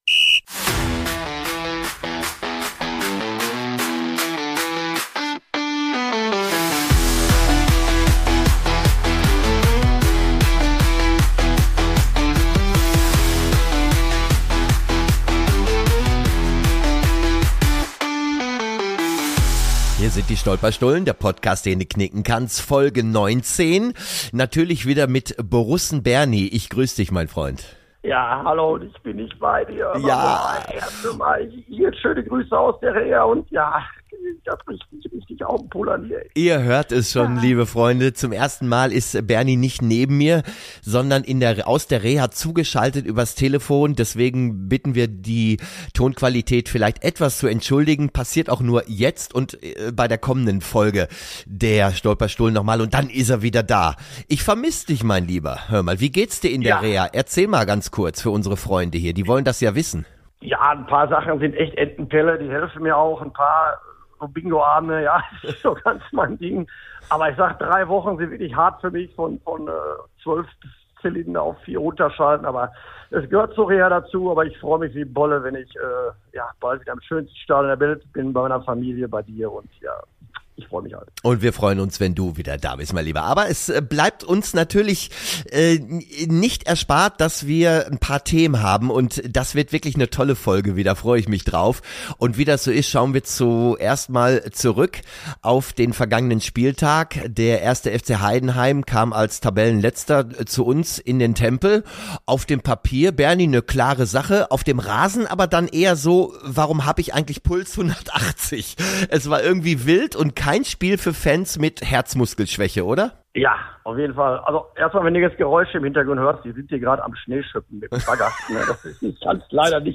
Natürlich gibt’s auch Tipps – und am Ende 'mal wieder ein kleines Liedchen.